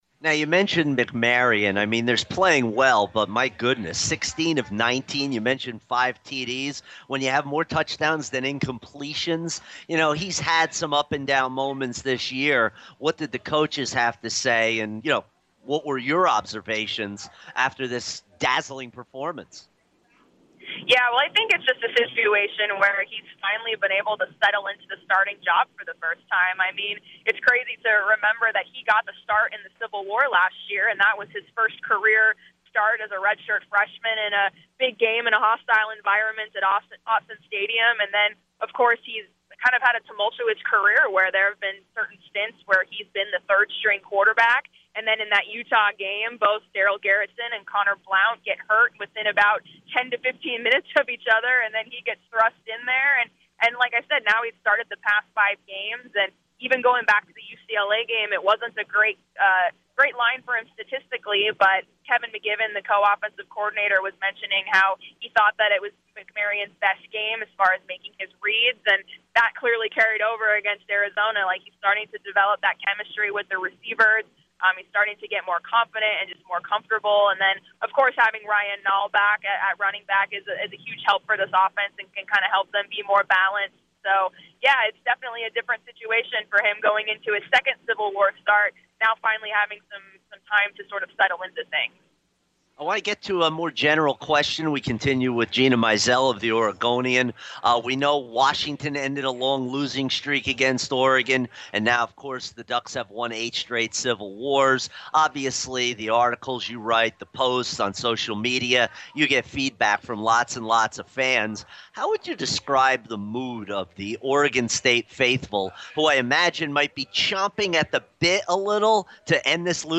(First minute lost)